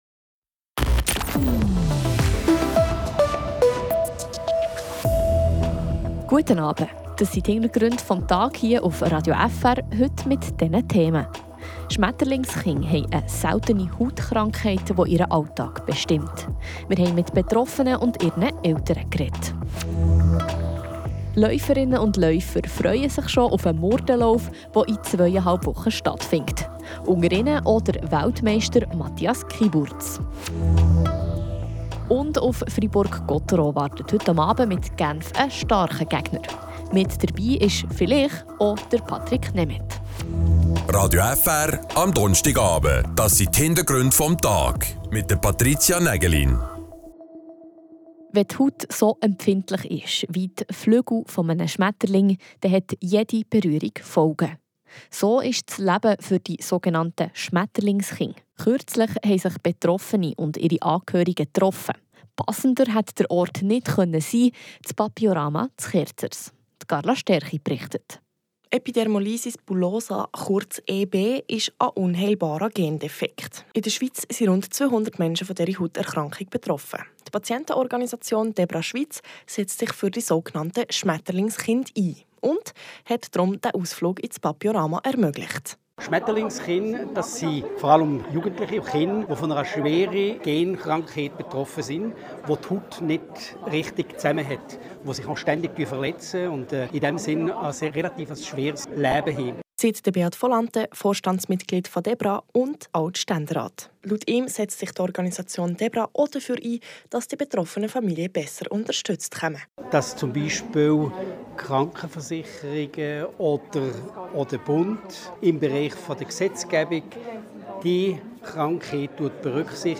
Wir sprechen mit einem der berühmtesten Teilnehmenden: der achtfache OL-Weltmeister Matthias Kyburz.
Wir sprechen mit dem Schweden Patrik Nemeth über seinen womöglich ersten Einsatz für die Freiburger.